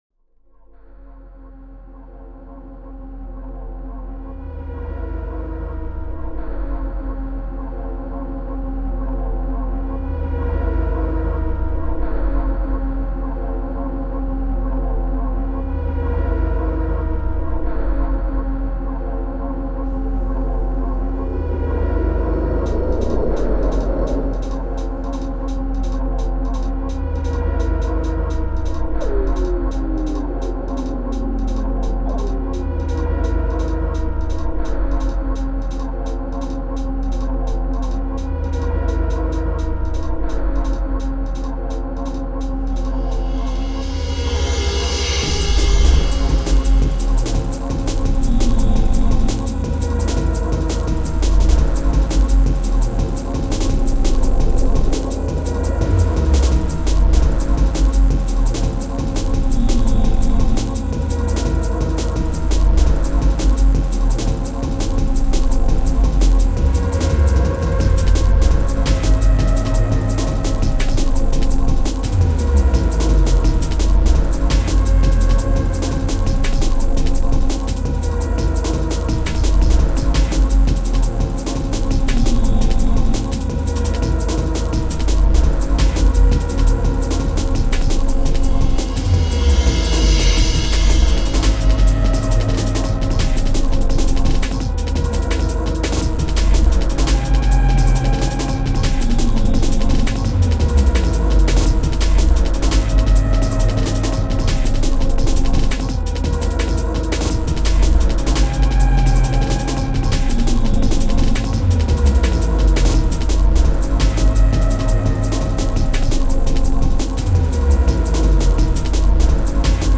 Impulse Tracker Module  |  1998-06-30  |  618KB  |  2 channels  |  44,100 sample rate  |  5 minutes, 38 seconds
style jungle
bpm 170